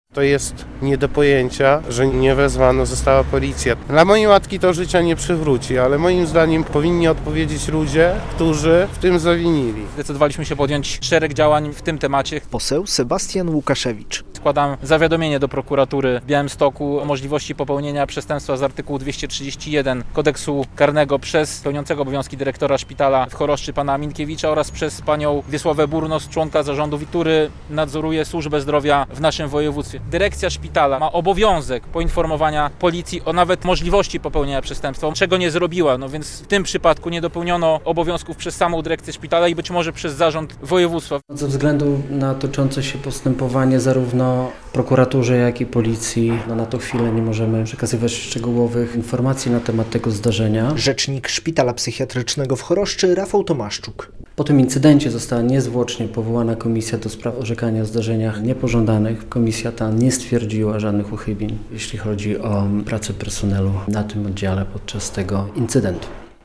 Konferencja prasowa PiS ws. wypadku, 10.02.2025, fot.
Podlascy działacze PiS-u składają do prokuratury zawiadomienie w sprawie wypadku pacjentki szpitala psychiatrycznego w Choroszczy - relacja